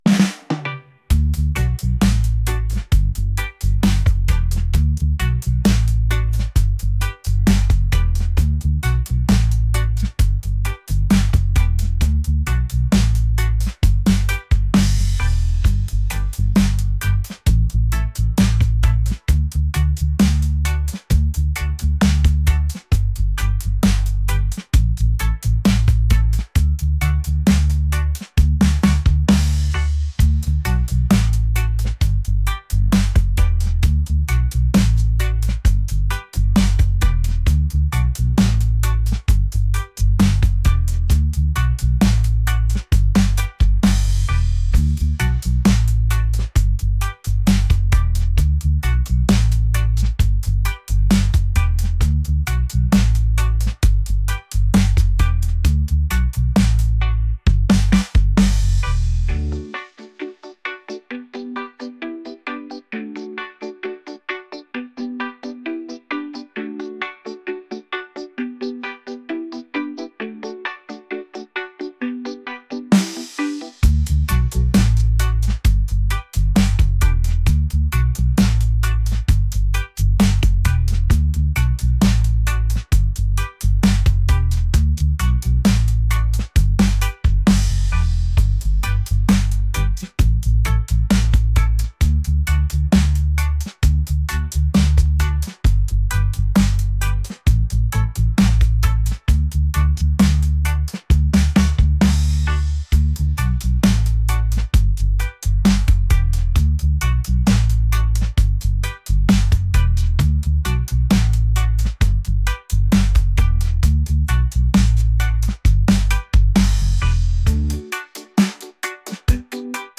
positive | reggae | upbeat